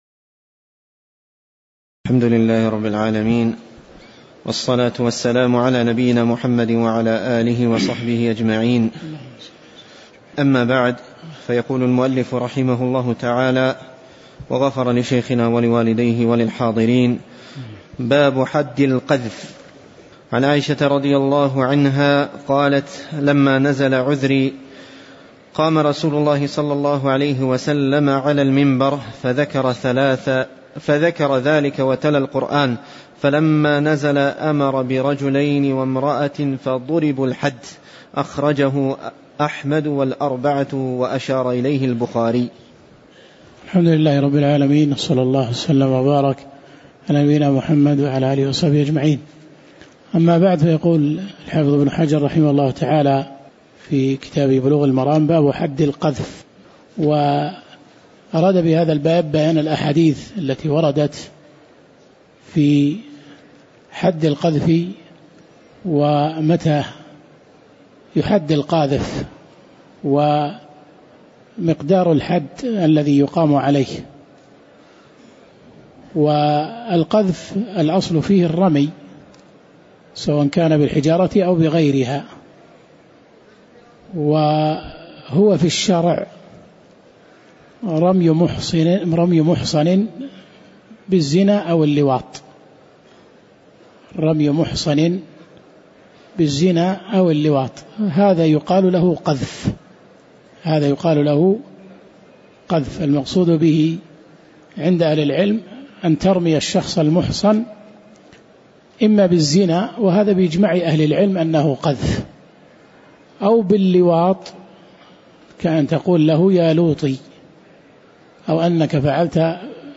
تاريخ النشر ٨ محرم ١٤٤٠ هـ المكان: المسجد النبوي الشيخ